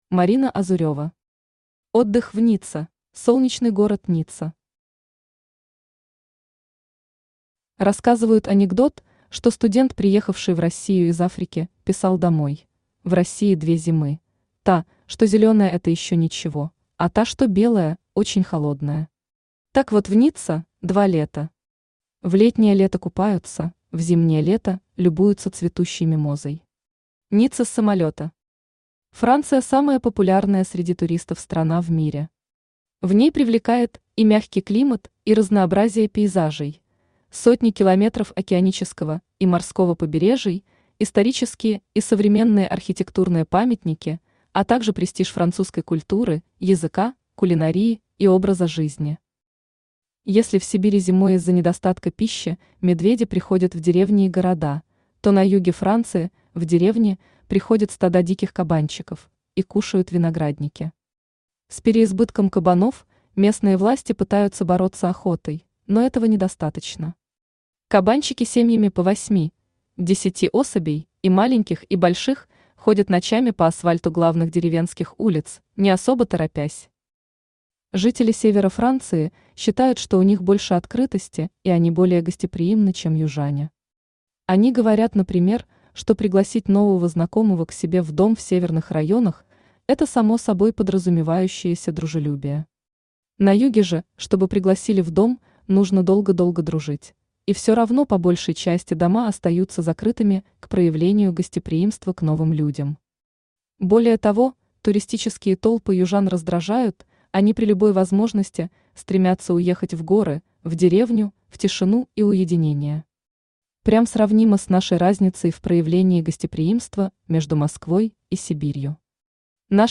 Аудиокнига Отдых в Ницце | Библиотека аудиокниг
Aудиокнига Отдых в Ницце Автор Марина Азурева Читает аудиокнигу Авточтец ЛитРес.